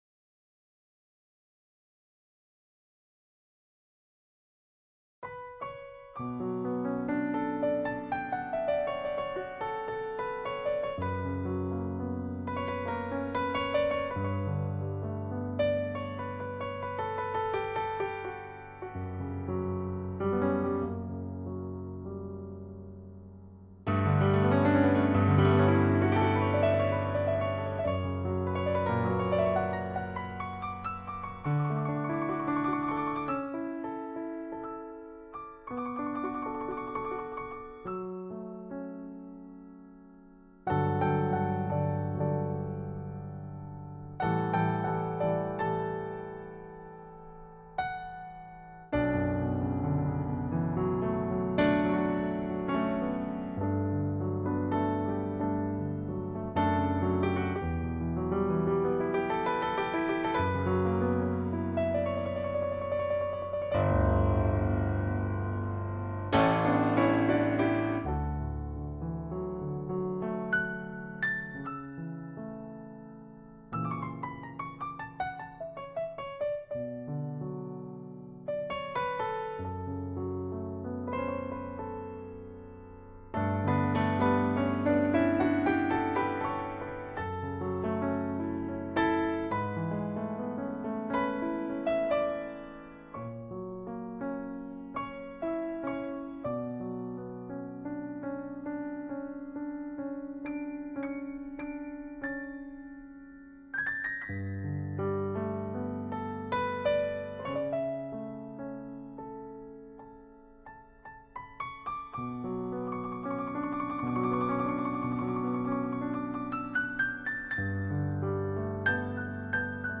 Solo de  Piano para Relajarse.mp3